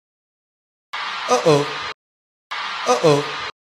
My new text tone is Hobi saying “uh oh” after he dropped his glove at Lollapalooza🥺